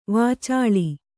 ♪ vācāḷi